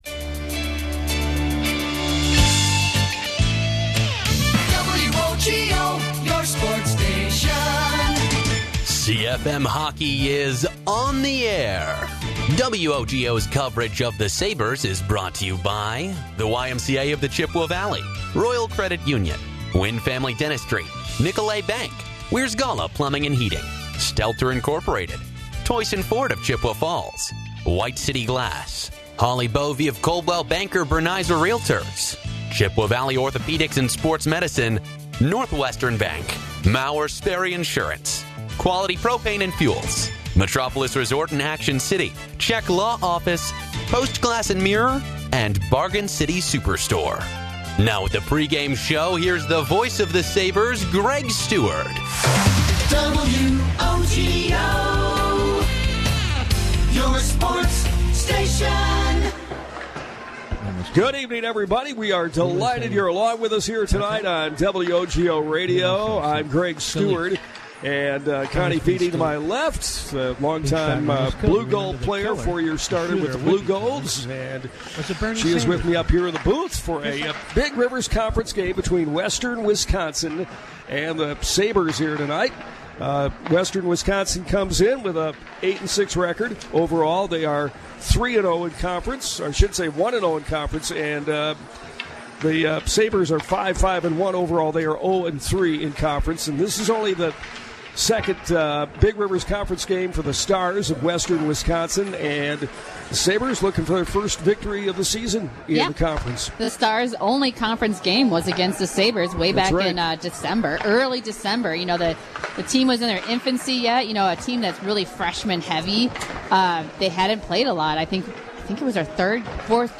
had the call on WOGO Radio as the Chippewa Falls/Menomonie Sabers played the Western Wisconsin Stars at The Chippewa Ice Arena on 1/13/26